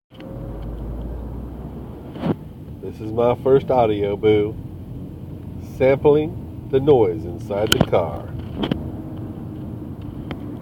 sample car noise